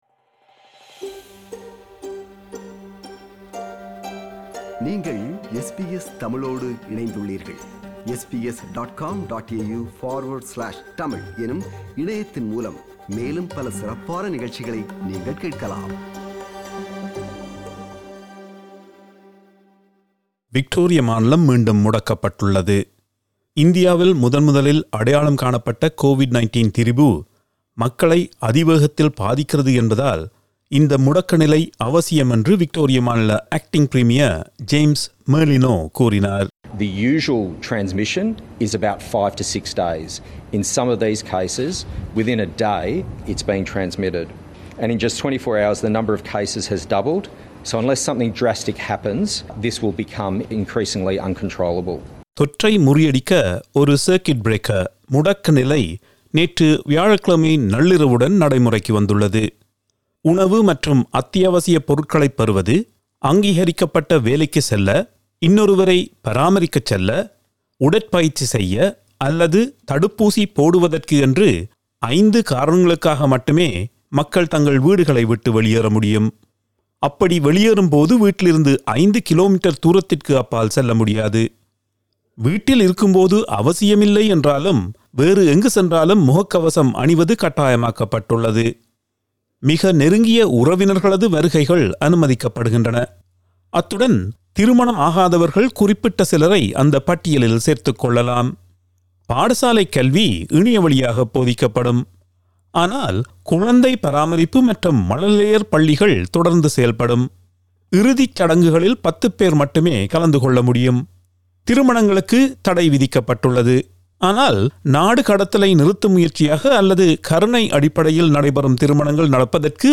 reports in Tamil